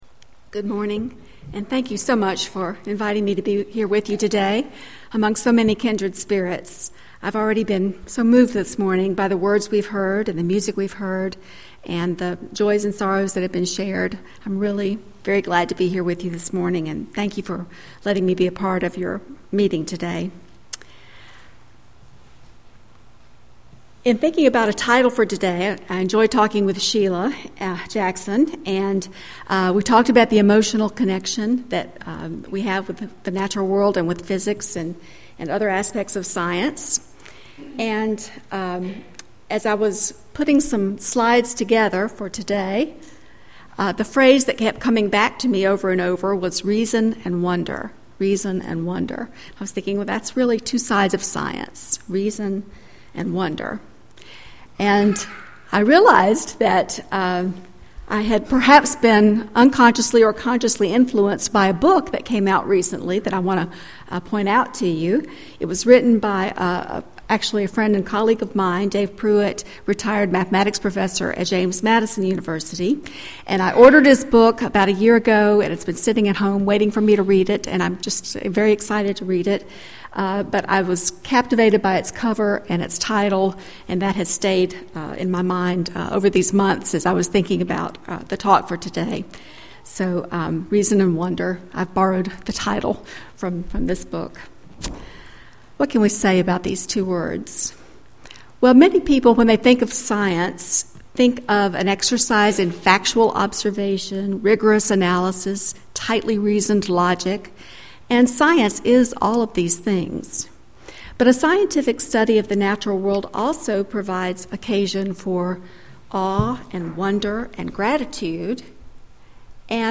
But a scientific study of the natural world also provides occasions for awe, wonder, and gratitude, responses that some might call expressions of religious emotion. This talk will offer several examples from the fields of astronomy, astrophysics and cosmology that offer “reason and wonder.”